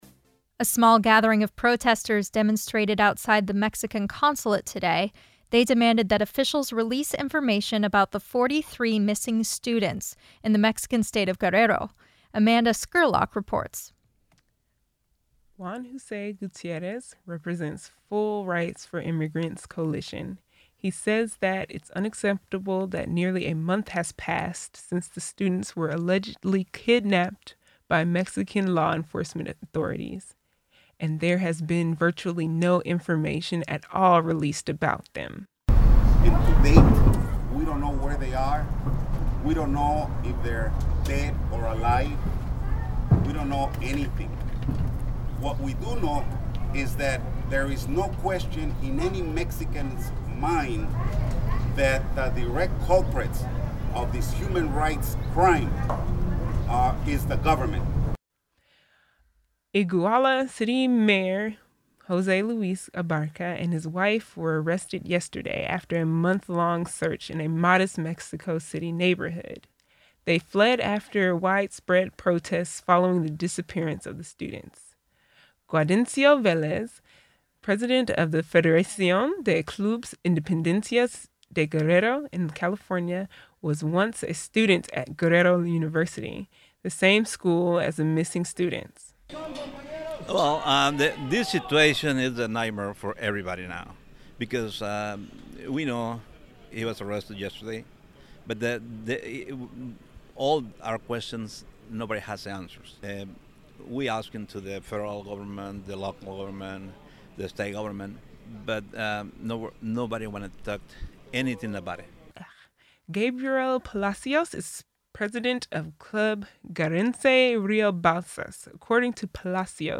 Leaders of Latino organizations gathered to protest against the Mexican government in front of the Mexican Consulate in Downtown Los Angeles this morning.
Speeches were in both Spanish and English, some demanding the whereabouts of the students, whether they are dead or alive.
Mexican Consulate Rally.mp3